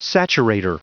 Prononciation du mot saturator en anglais (fichier audio)
Prononciation du mot : saturator